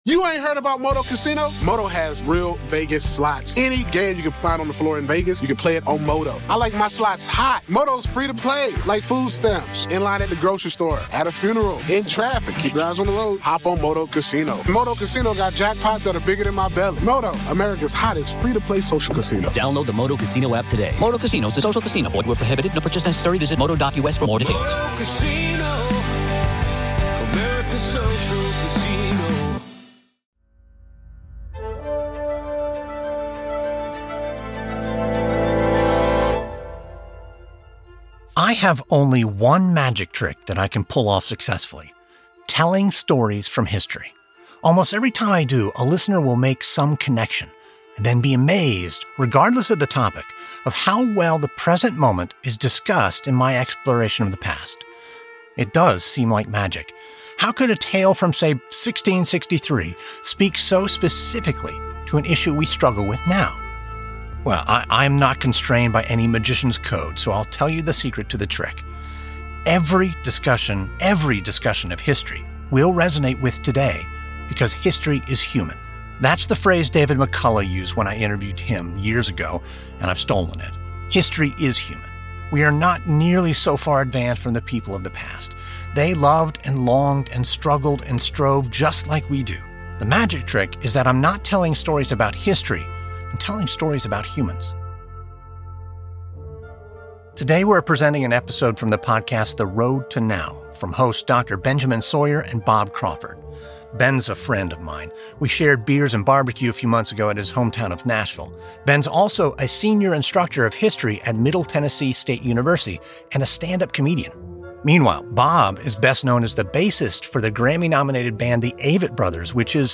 In this week’s Saturday Matinee episode, we’re bringing you a podcast episode from a friend, interviewing a legend.